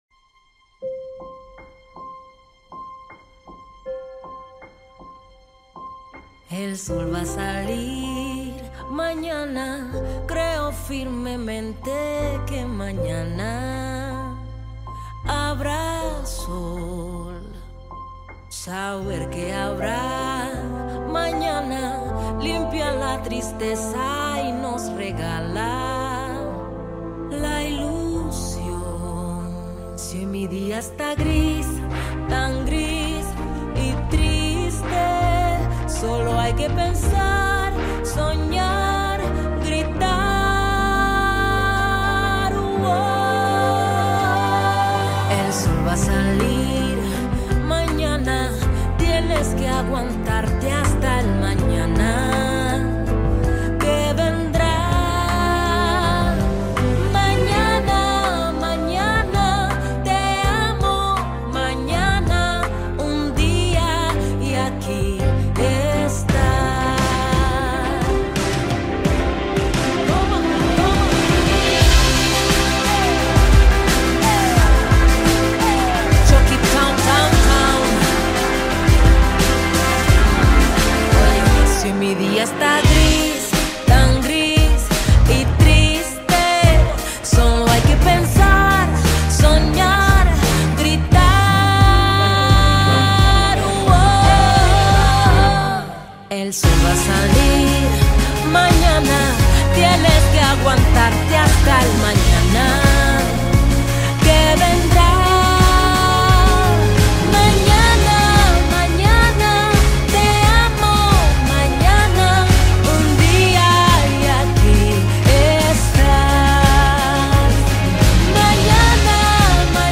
un toque latino